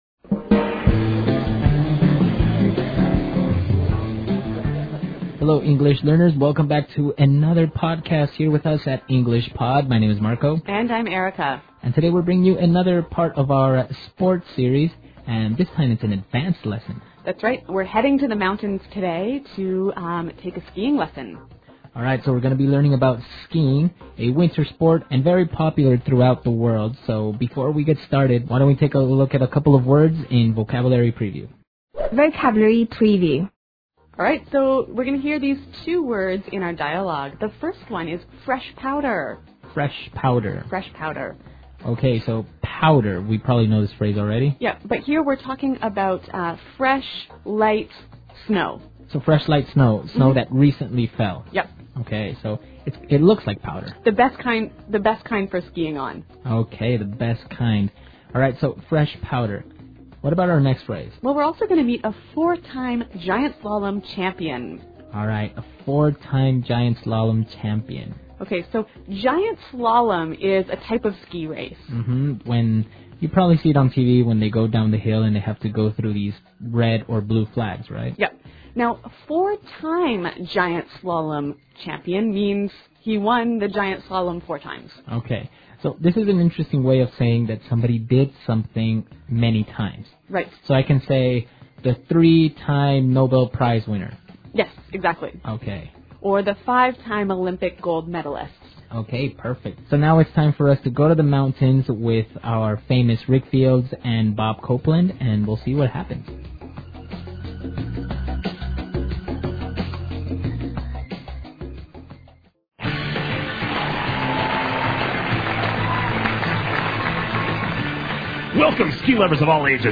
纯正地道美语 第103期(外教讲解):Skiing 听力文件下载—在线英语听力室